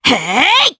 Valance-Vox_Attack4_jp.wav